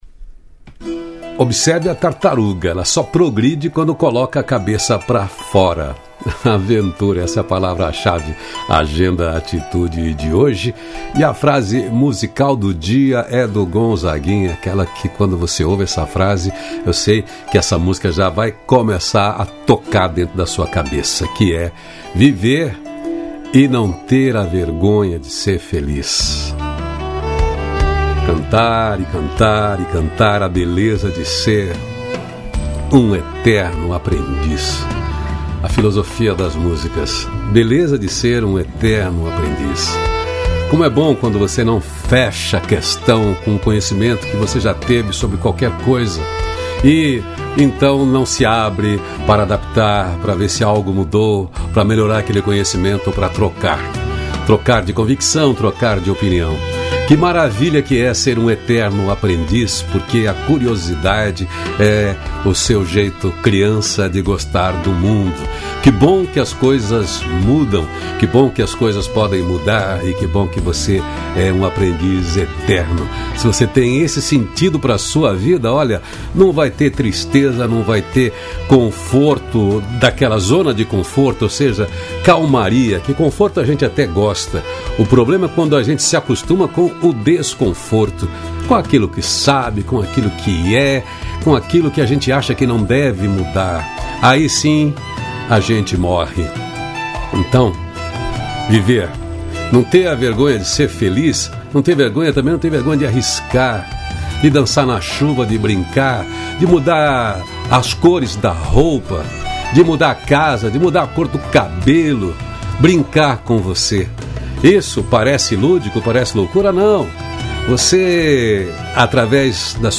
Aventura é palavra chave de hoje da nossa conversa. E a frase musical que está no apoio é "Viver e não ter a vergonha de ser feliz. Cantar a beleza de ser um eterno aprendiz"…